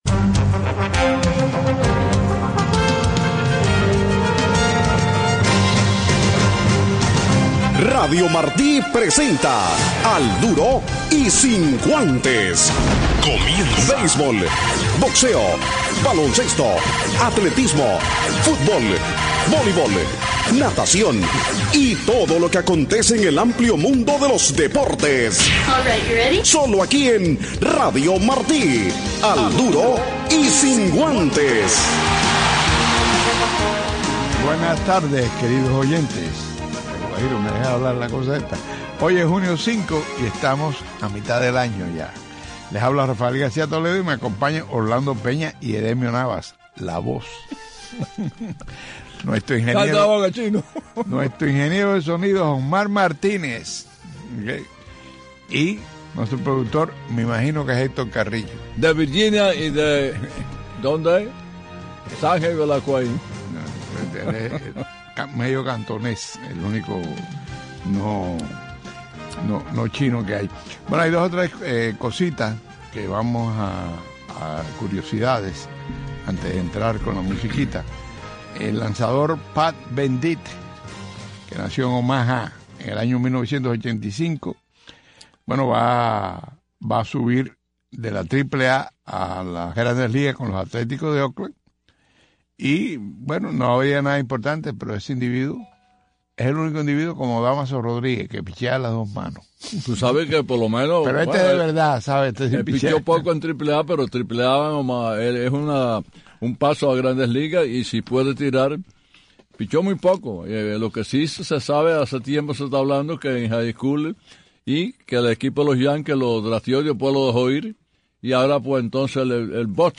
Los ultimos segundos de la grabación quedó cortado.